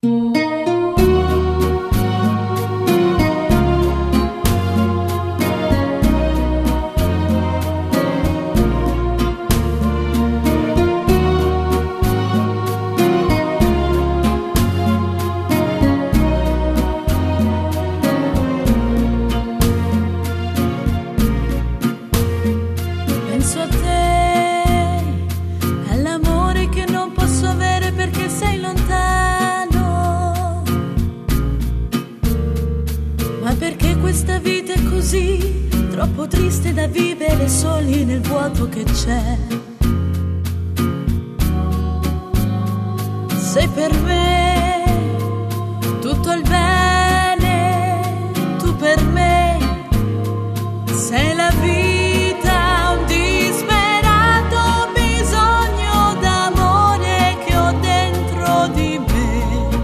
Rumba lenta